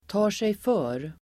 Uttal: [ta:r_sejf'ö:r]